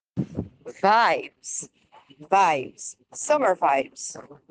ATTENTION TO PRONUNCIATION 🗣